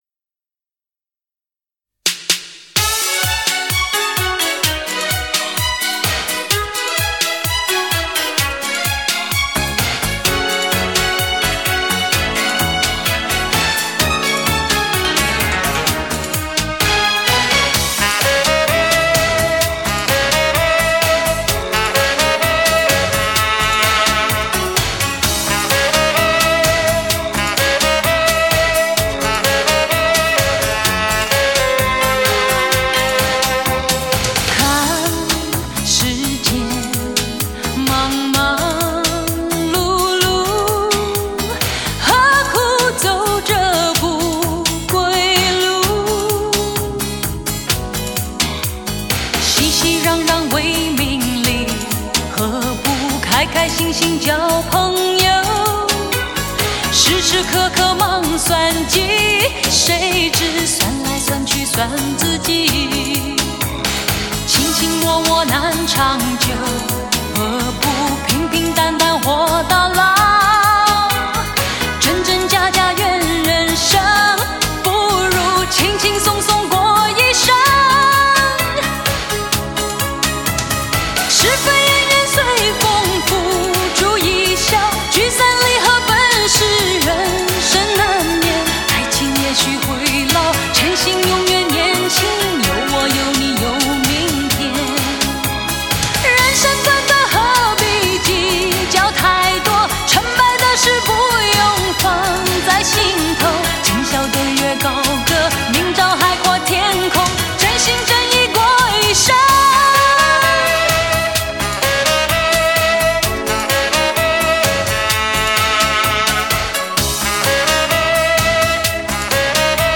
HI-FI顶级人声测试天碟
无损音乐